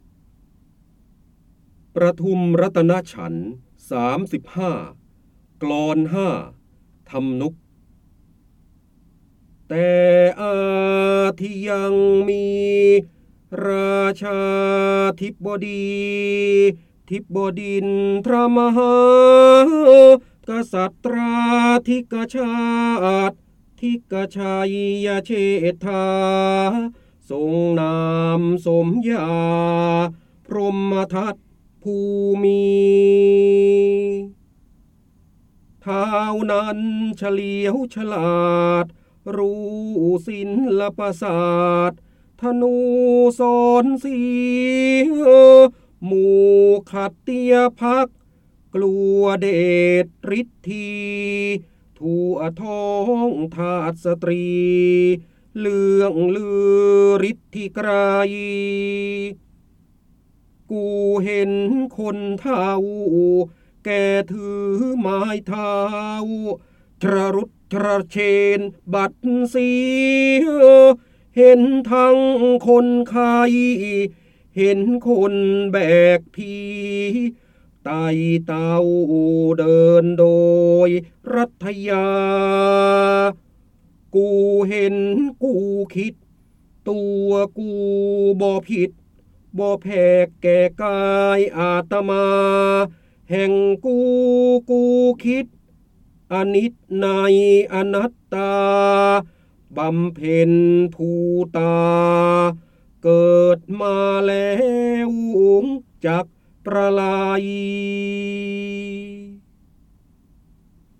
เสียงบรรยายจากหนังสือ จินดามณี (พระโหราธิบดี) ประทุมรัตนฉันท ๓๕ กลอน ๕ ฯ ทำนุก ฯ
คำสำคัญ : จินดามณี, พระเจ้าบรมโกศ, การอ่านออกเสียง, ร้อยกรอง, ร้อยแก้ว, พระโหราธิบดี
ลักษณะของสื่อ :   คลิปเสียง, คลิปการเรียนรู้